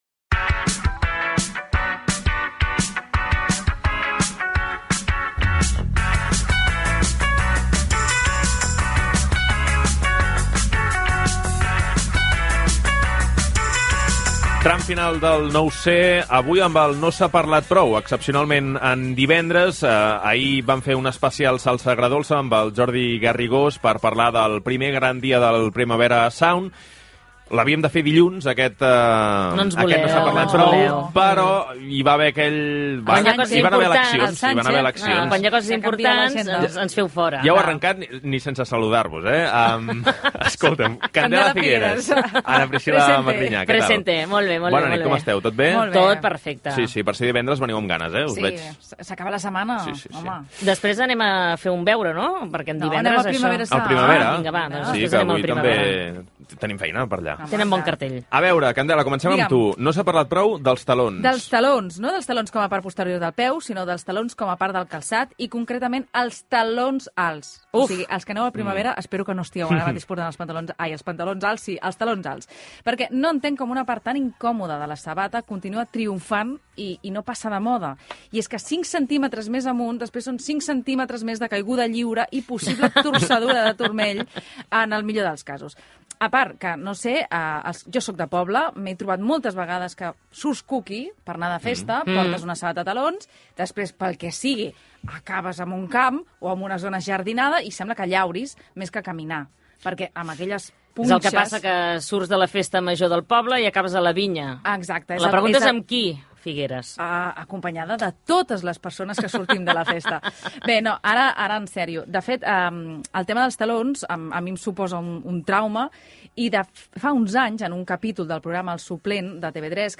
Secció "No se n'ha parlat prou": el talons alts, la revista "Lecturas" Gènere radiofònic Informatiu